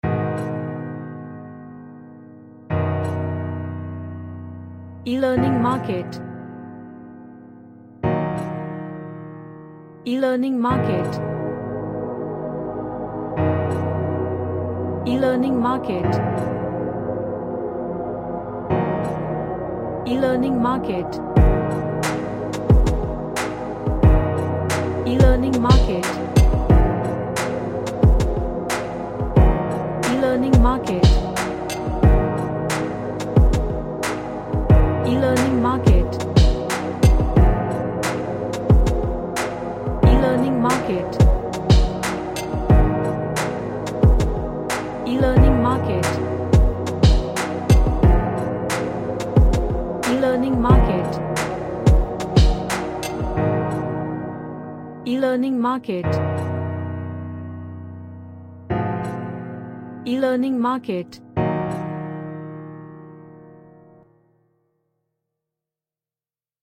A nice Airy Chordal Track.
Relaxation / Meditation